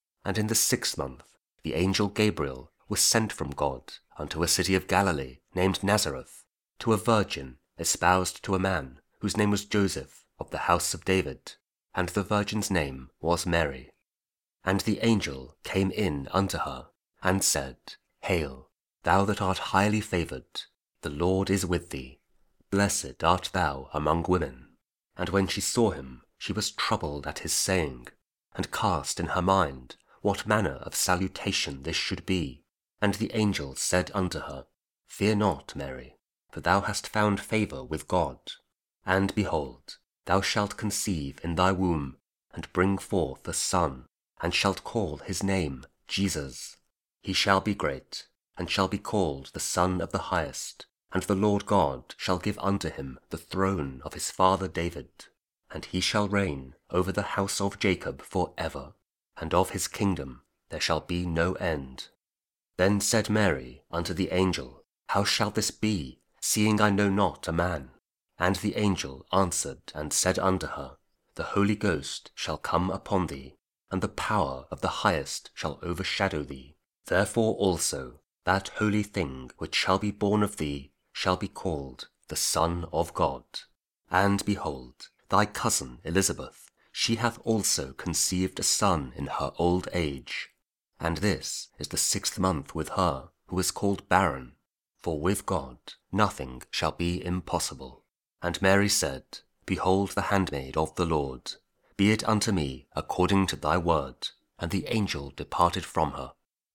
Luke 1: 26-38 – 4th Sunday of Advent Year B, also 20th December Weekday (KJV Audio Bible)